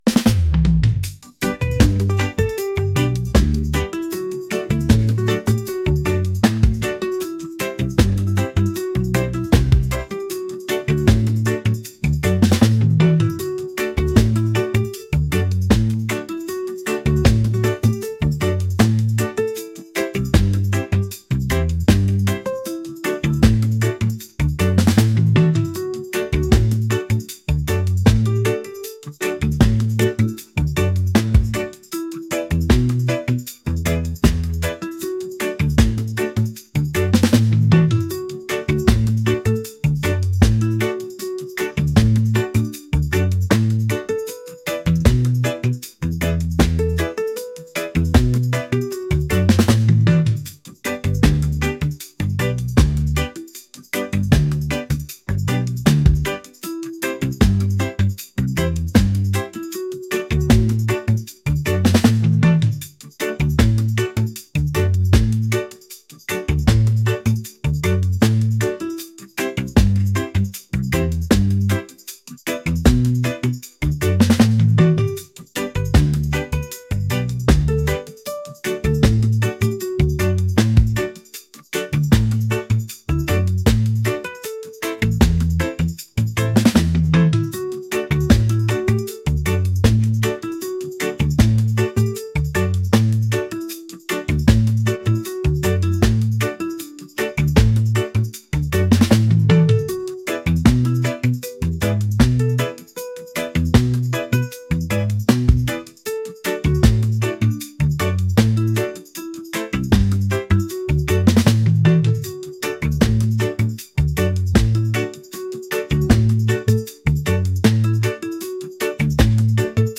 mellow | reggae